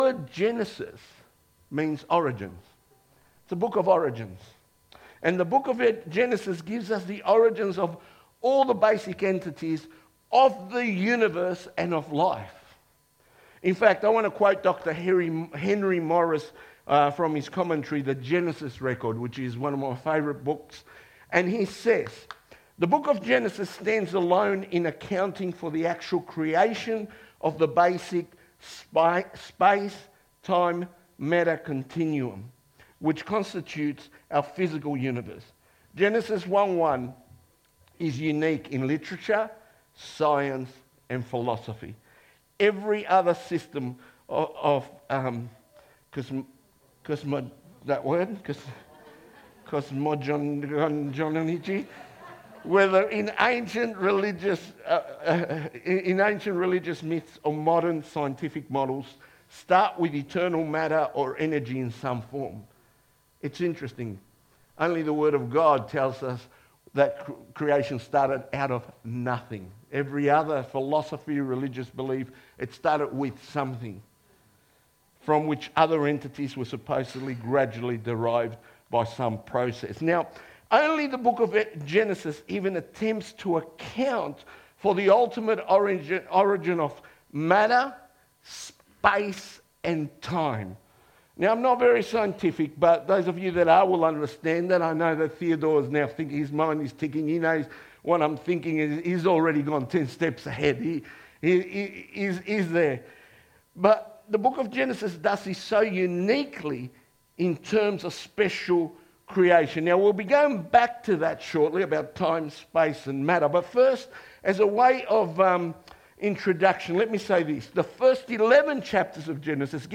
2025 • 30.15 MB Listen to Sermon Download this Sermon Download this Sermon To download this sermon